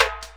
Africa Stick Perc.wav